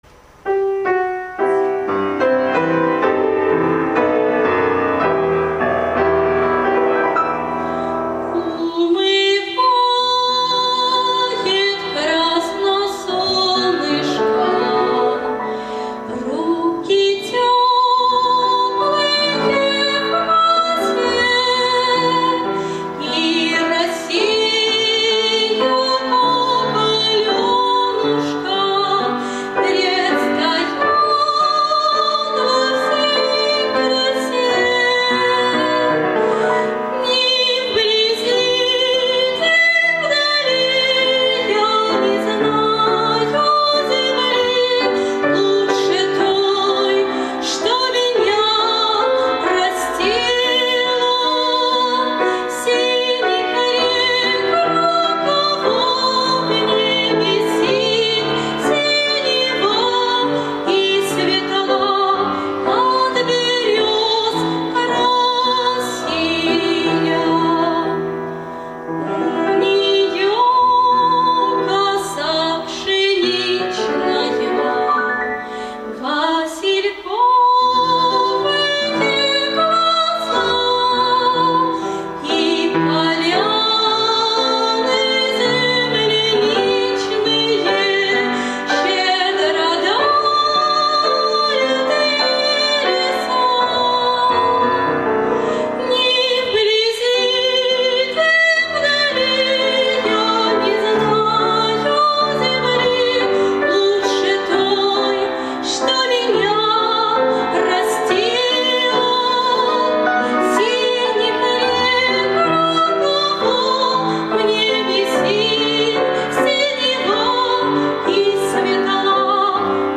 Сольный концерт